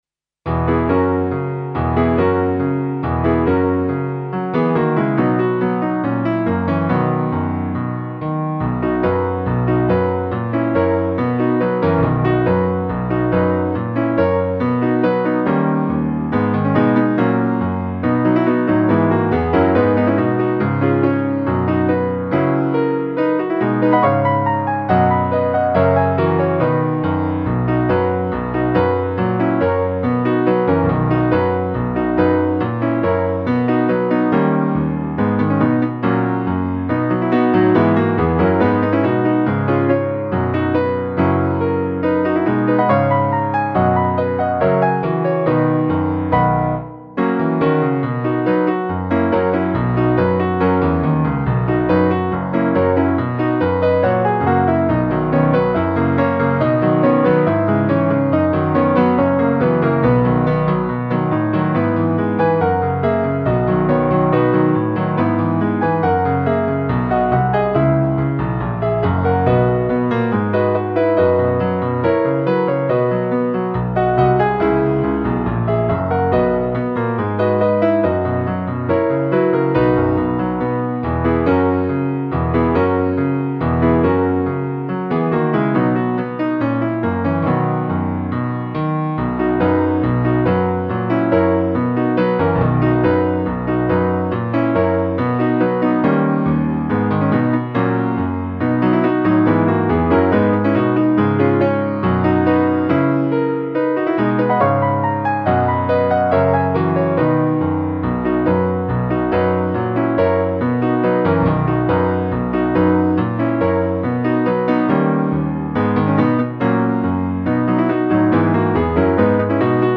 (Base Mp3)
pipposuperstar base.mp3